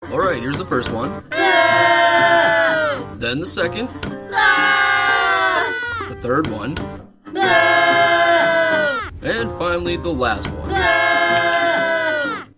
Scared eggs - Botón de sonido